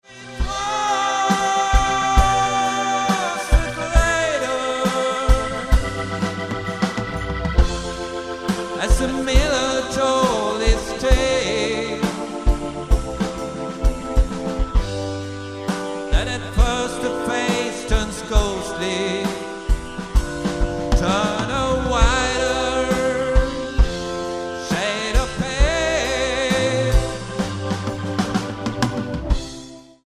Smakprov 2, inspelat live på Etage 2004:
Fullfjädrat coverband.